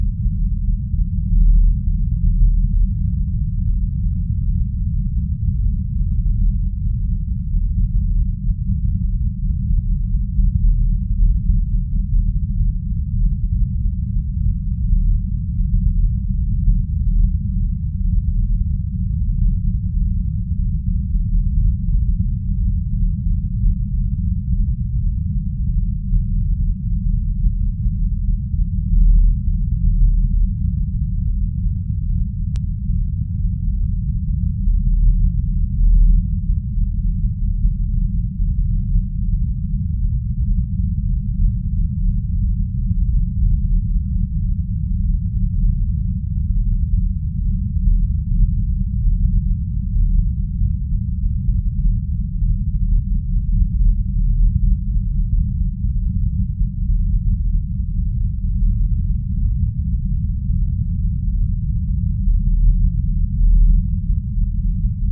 warpengine.ogg